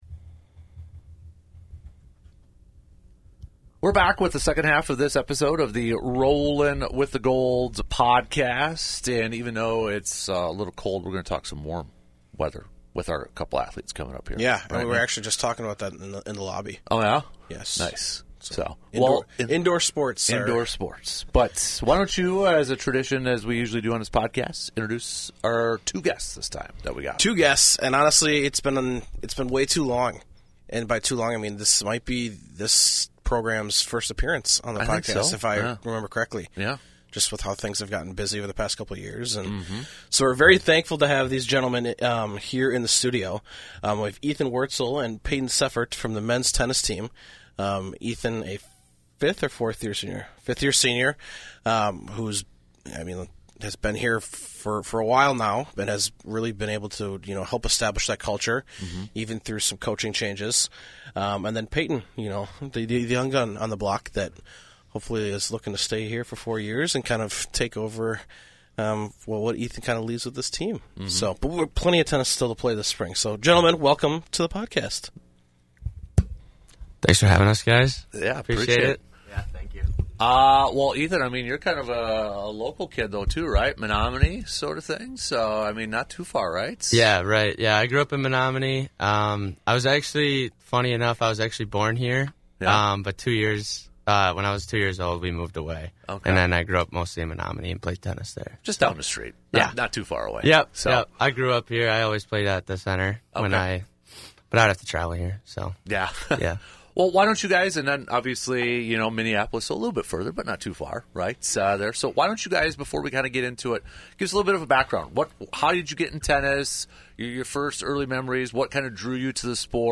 Then the boys are joined by a couple members of the Blugold men's tennis team as they get set to start their season back up at the beginning of February!